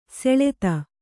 ♪ seḷeta